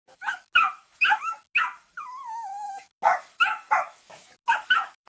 Puppy Baby Sound Button - Free Download & Play